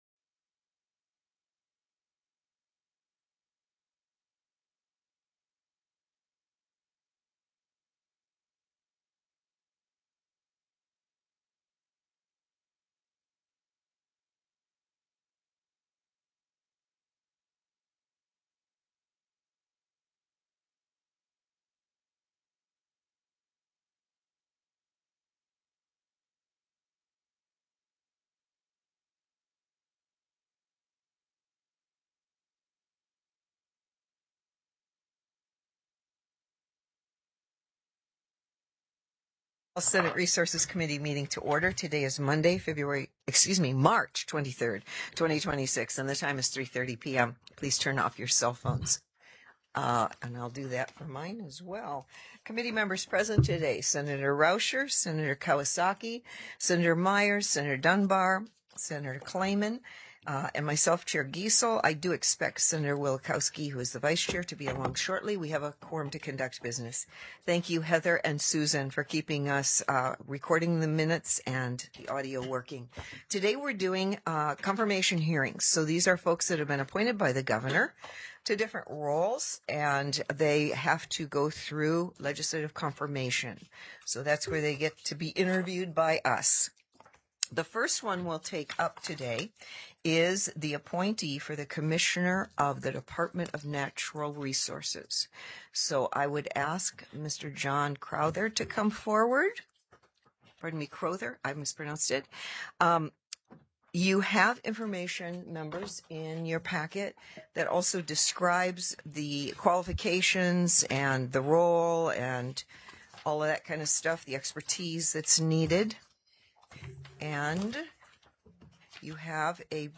The audio recordings are captured by our records offices as the official record of the meeting and will have more accurate timestamps.
Confirmation Hearing(s): Department of Natural Resources (dnr) - Commissioner